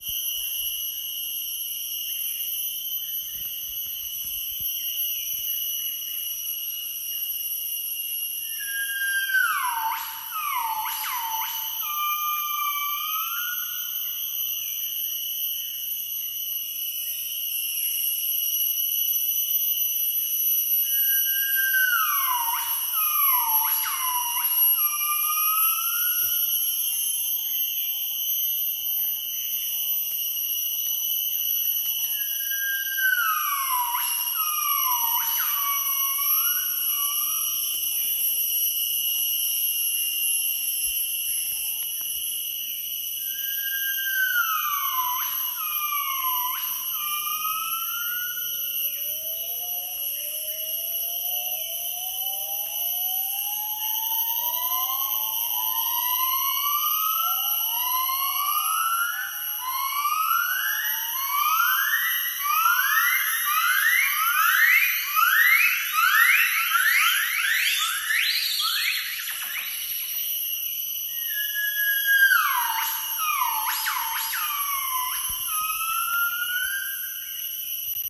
Nomascus annamensis
Category: Songs
Nomascus-annamensis.m4a